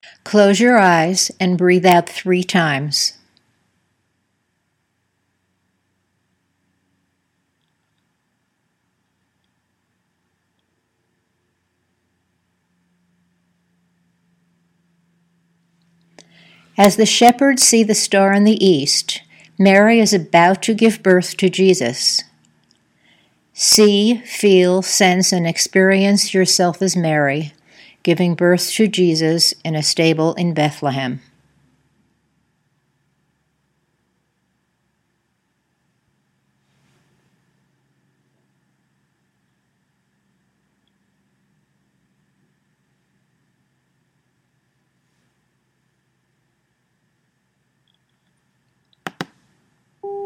When an Imagery instruction is complete, there are 15 seconds of silence on the tape for your Imagery to emerge.  When that time is over, you’ll hear a tone that is signaling you to breathe out one time and open your eyes.